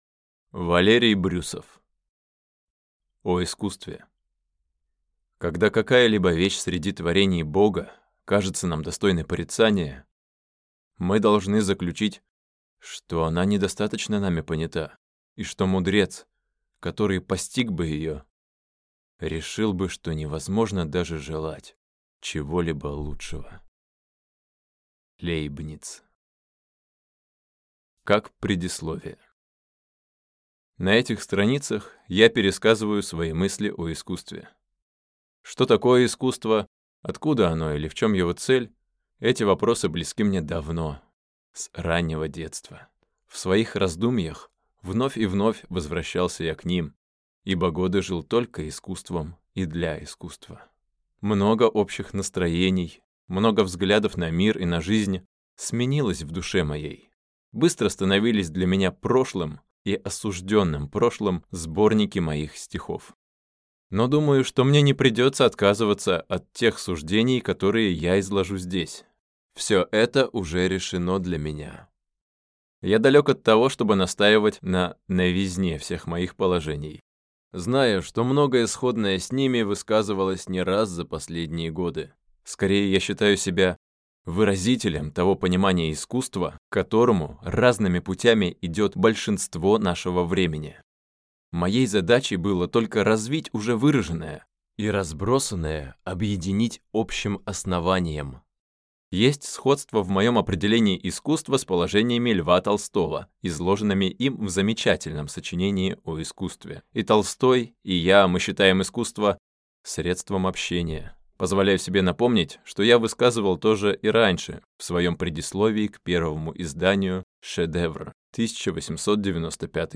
Аудиокнига О искусстве | Библиотека аудиокниг